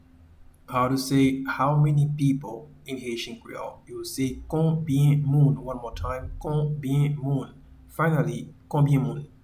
Pronunciation:
How-many-people-in-Haitian-Creole-Konbyen-moun.mp3